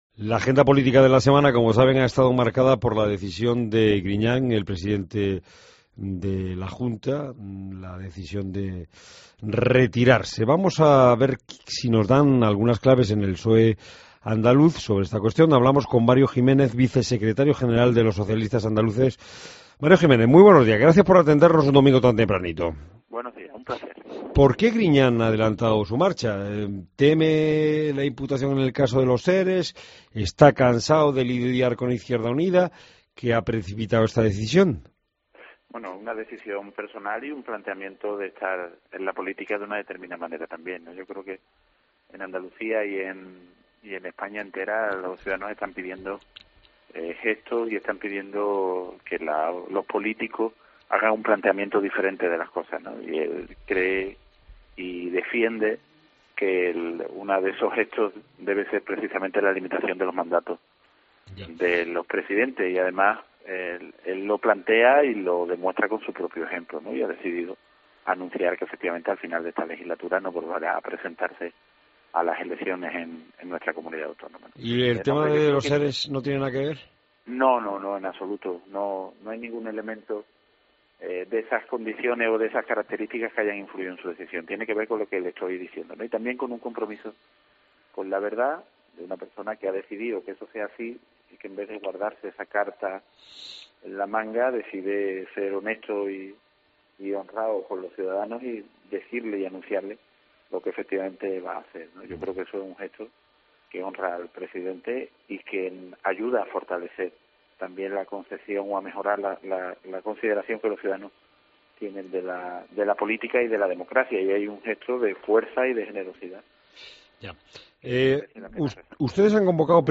Escucha la entrevista a Mario Jiménez, número 2 del PSOE-A